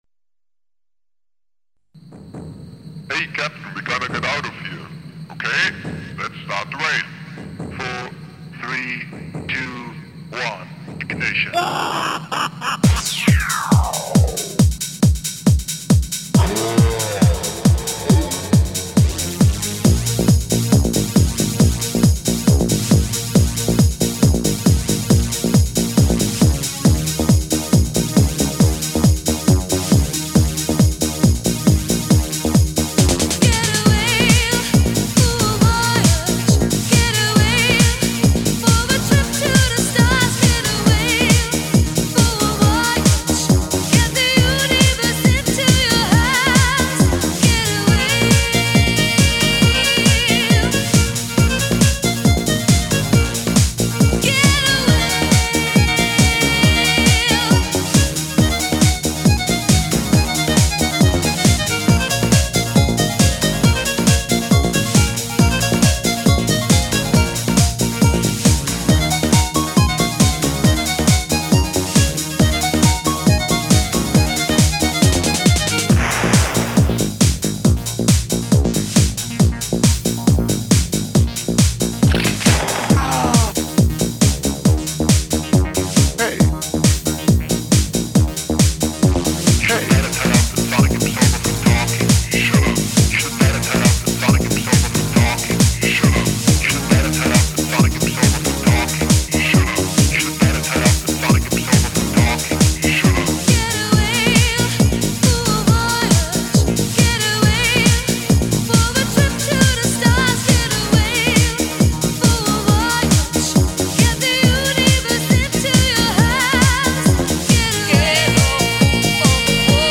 OPL2/Adlib-Tune